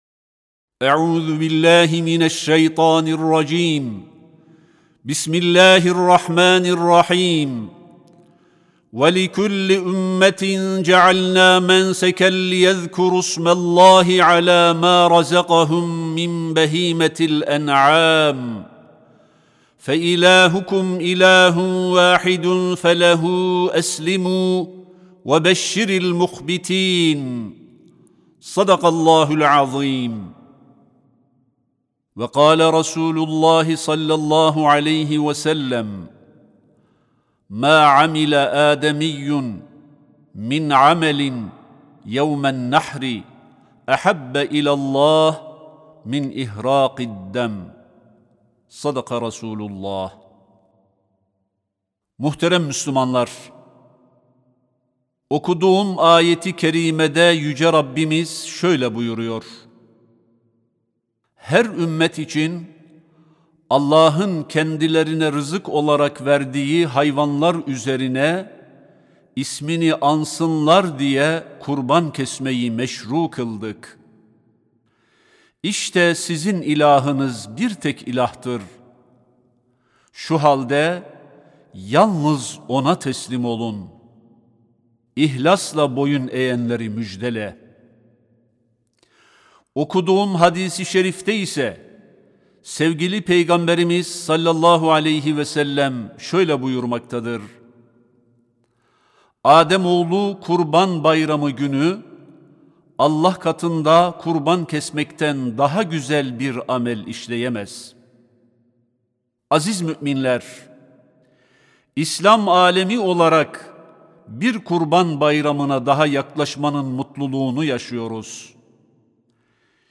CUMA HUTBESİ: KURBANINI PAYLAŞ, KARDEŞİNLE YAKINLAŞ- SESLİ HABER
Kurbanını-Paylaş-Kardeşinle-Yakınlaş-Sesli-Hutbe.mp3